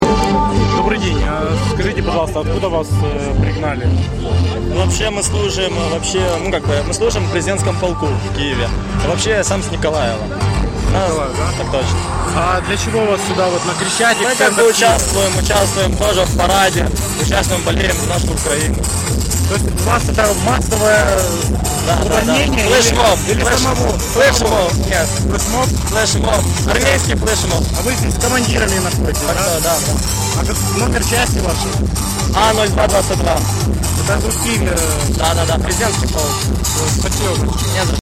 Около трех сотен бритых молодых людей, которые прибыли в киевскую фан-зону на Крещатике и которых из-за их внешнего вида приняли за скинхедов, оказались военнослужащими Президентского полка.
По их словам, они находятся в фан-зоне для того, чтобы поддержать сегодня сборную Украины. Как сказал один молодой человек, они организовали своеобразный «армейский флеш-моб».